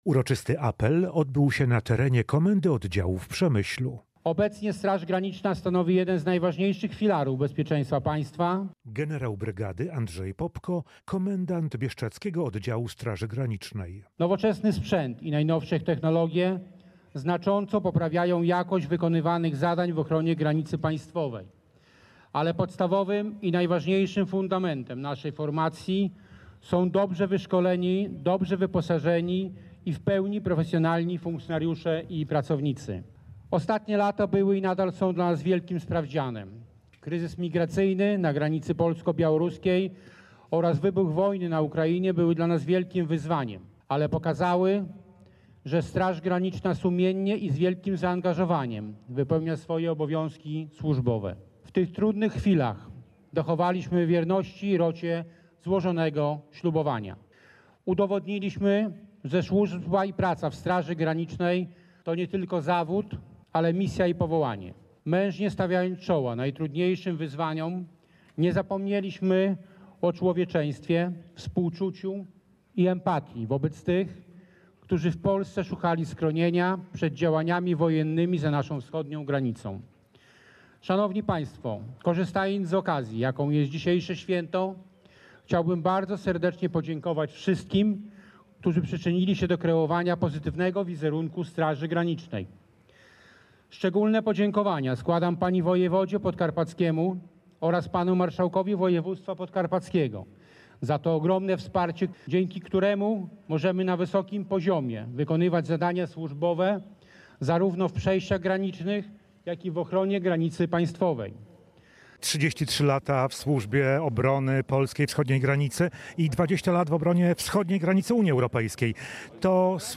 W Przemyślu odbyły się uroczystości 33. rocznicy powstania Bieszczadzkiego Oddziału Straży Granicznej. Po mszy w sanktuarium Męki Pańskiej w Kalwarii Pacławskiej, na terenie Komendy Oddziału w Przemyślu odbył się uroczysty apel. Wręczono blisko 100 odznaczeń państwowych, resortowych i odznak Straży Granicznej.
Jak zaznaczył komendant bieszczadzkiego oddziału generał brygady Andrzej Popko, Straż Graniczna pełni dziś jedną z najważniejszych funkcji w ochronie ojczyzny.
Relacja